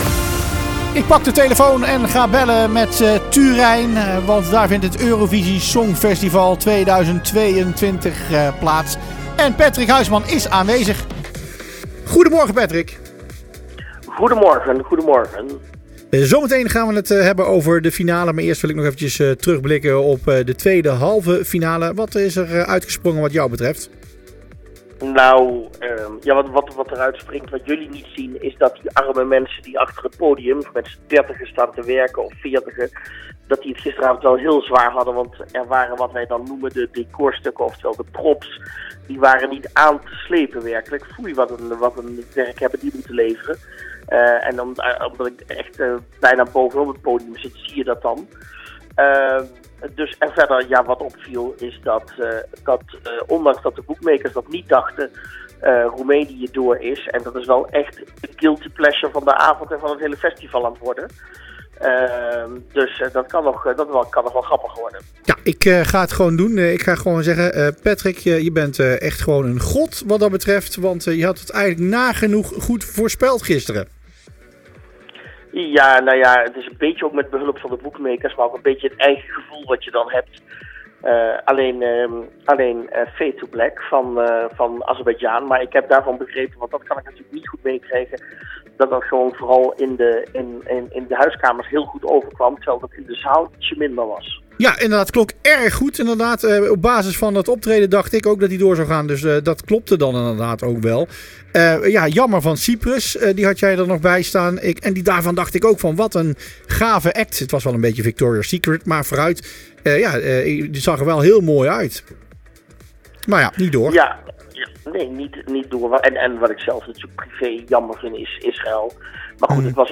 Live-verslag – Pagina 2